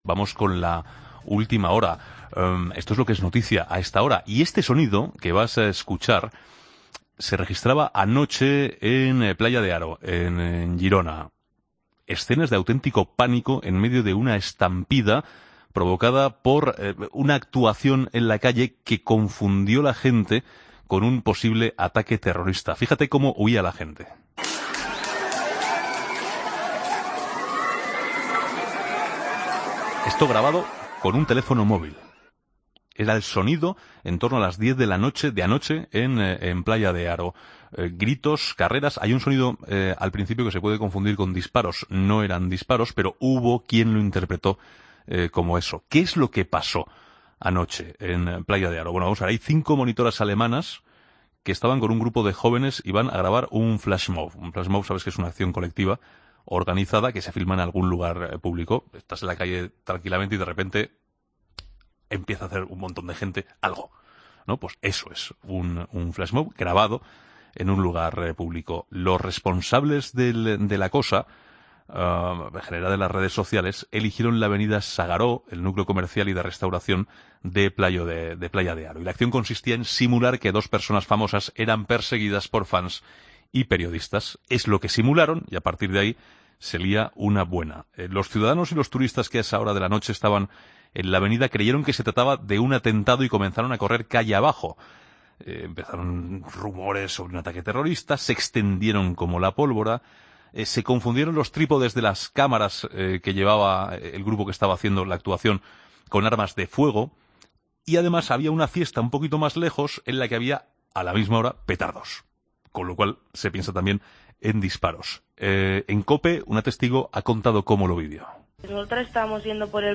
AUDIO: Escucha al concejal de Protección Civil de Castell-Playa de Aro, Josep María Solé, en 'La Tarde'.